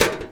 pgs/Assets/Audio/Metal/metal_tin_impacts_movement_rattle_02.wav
metal_tin_impacts_movement_rattle_02.wav